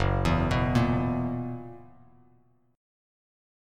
F#11 chord